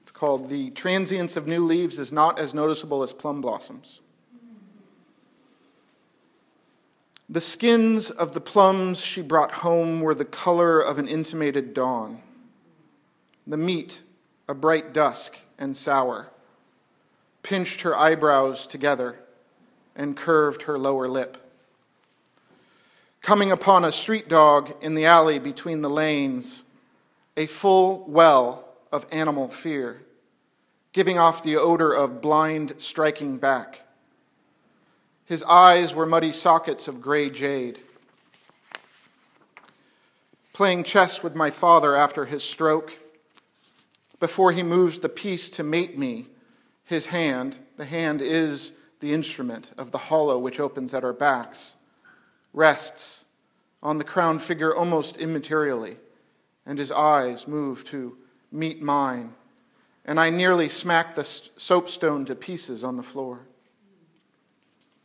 Listen to some of the poems read by the poet, and sign up to get notified when the book is ready, stay up to date on readings and other news.